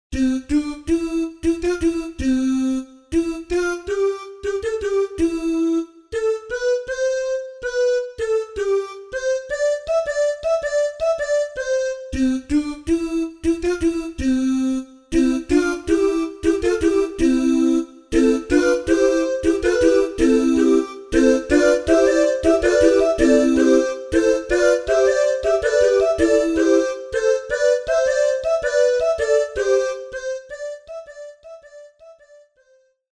RÉPERTOIRE  ENFANTS
CANONS